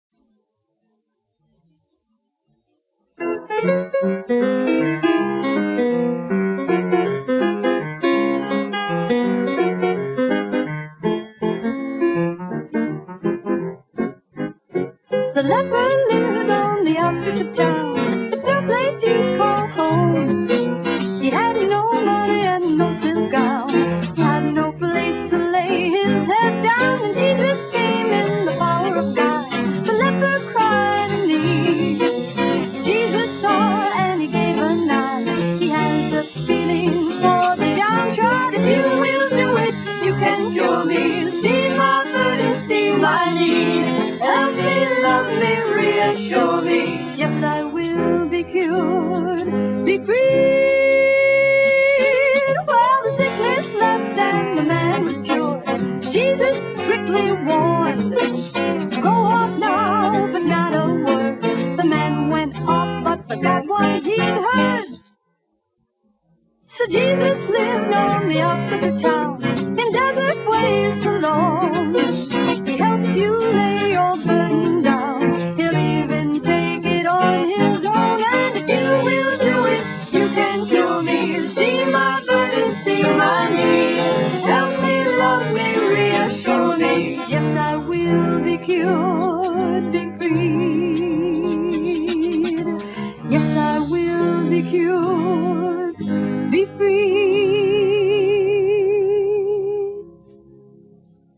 VOCALISTS
ORGAN, SYNTHESIZER
GUITAR, SYNTHESIZER
BASS GUITAR
DRUMS